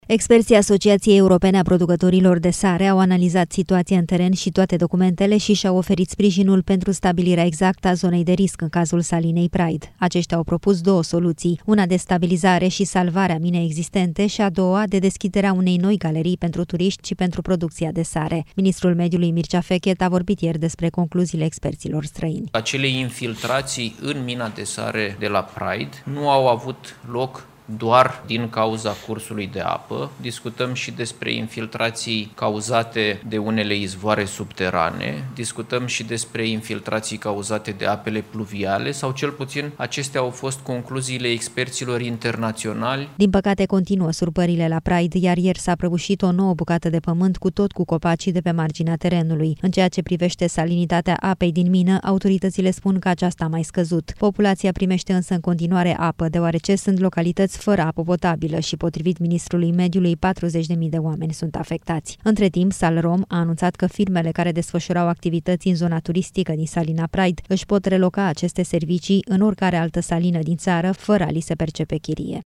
Ministrul Mediului, Mircea Fechet a vorbit ieri despre concluziile experților străini.